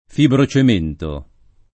[ fibro © em % nto ]